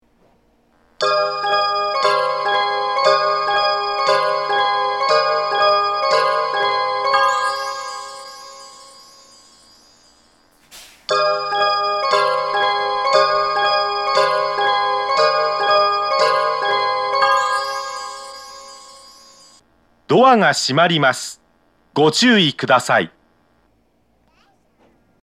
発車メロディーはテイチク製の曲で、音質が非常に良いです。
1.9コーラスの音声はモハE230特有のCP排水音が入ってます。
1.9コーラス